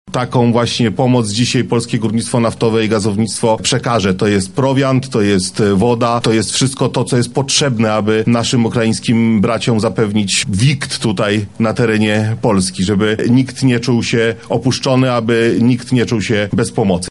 • mówi wicepremier Jacek Sasin.